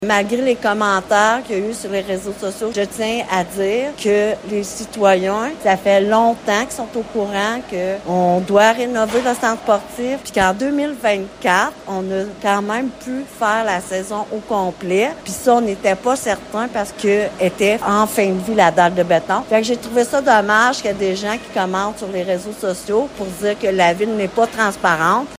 La mairesse, Francine Fortin, précise avoir mentionné les travaux à venir à de nombreuses reprises au cours de l’année :